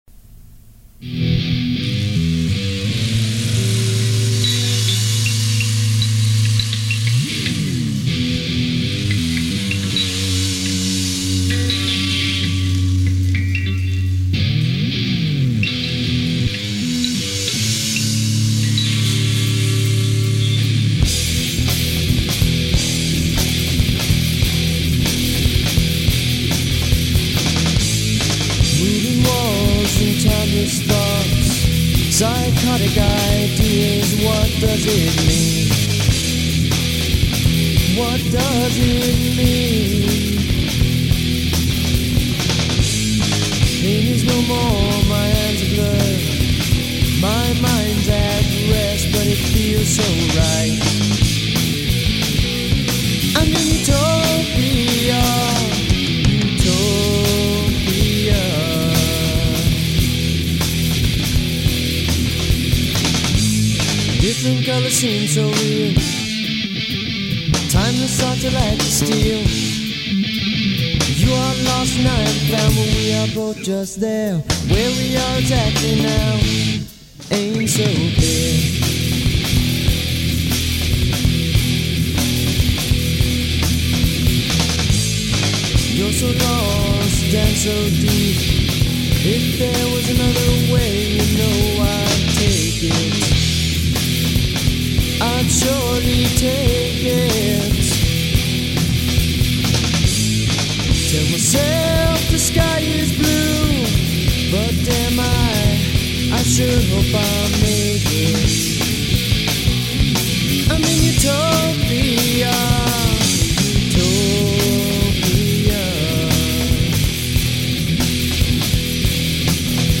From 1994-1997 I was in an Orange County, CA based rock band, UNLEDED.
vocals
Guitar